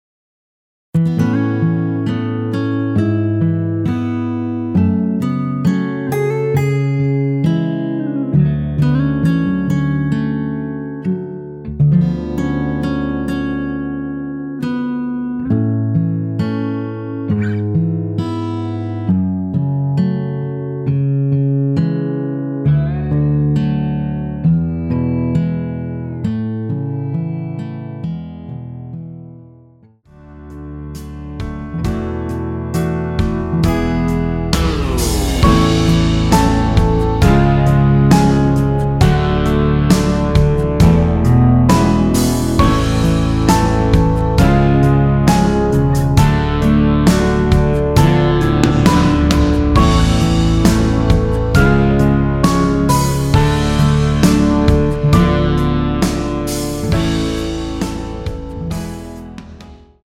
원키에서(-3) 내린 MR 입니다.
앞부분30초, 뒷부분30초씩 편집해서 올려 드리고 있습니다.
중간에 음이 끈어지고 다시 나오는 이유는